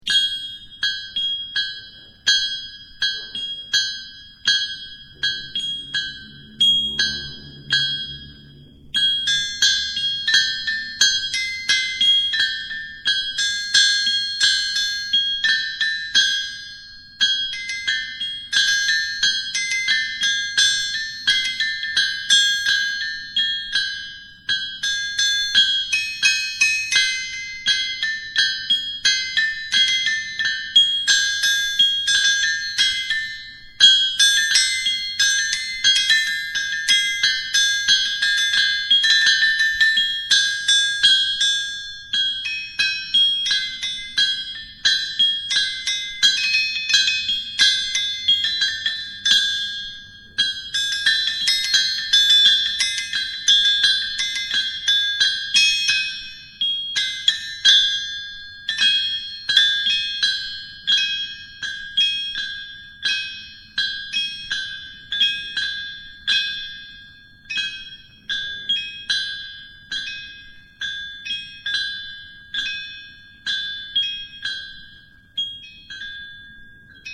Performance
Click here for a soundbite of the Odd Enjinears playing the anvil [1MB mp3]
They play homemade musical instruments that would make Dr Seuss proud.
The objects of Vulcan were transformed into beautiful music-making devices, some heavy and thrashing, others delicate and precious.
Melodies were tinkled off anvils; found objects were smacked onto large plastic container drums, and piano wire, suspended from the ceiling and pulled gently with thumb and forefinger, produced the most haunting of soundscapes.